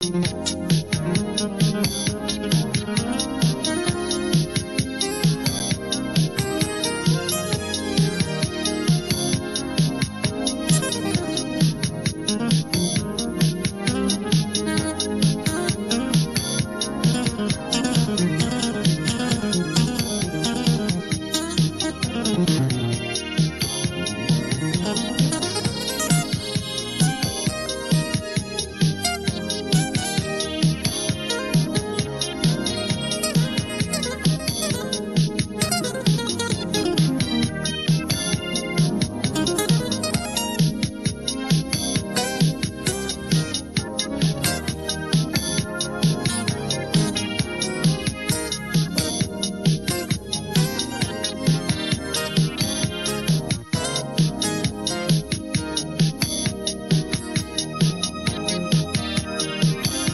Gospel / Boogie